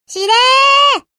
notif.mp3